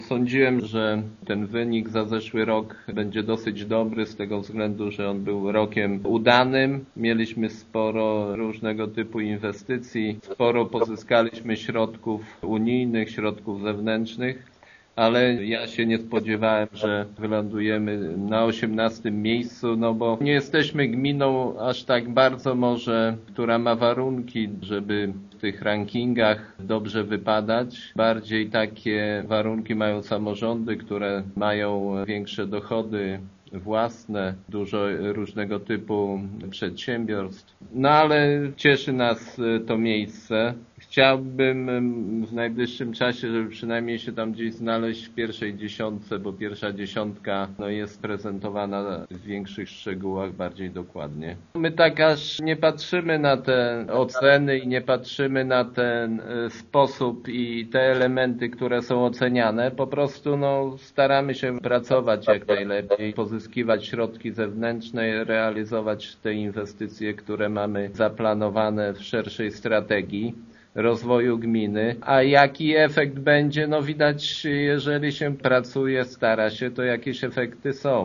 „Liczyliśmy na lepszy wynik niż w ubiegłym roku, ale nie spodziewaliśmy się aż takiego awansu” – przyznaje w rozmowie z Informacyjną Agencją Samorządową wójt Jacek Anasiewicz: